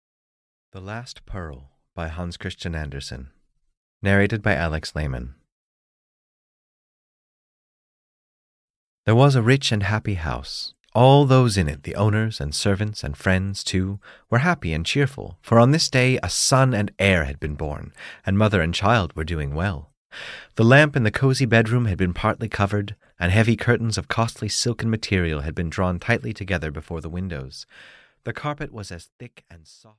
The Last Pearl (EN) audiokniha
Ukázka z knihy